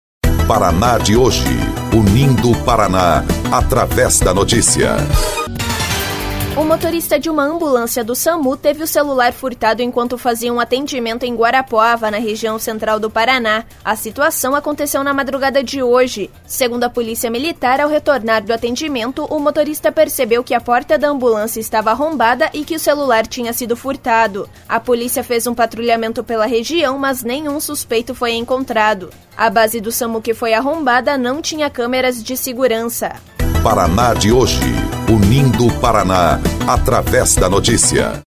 BOLETIM – Motorista do Samu tem celular furtado em ambulância